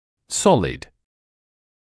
[‘sɔlɪd][‘солид]твёрдый; плотный; цельный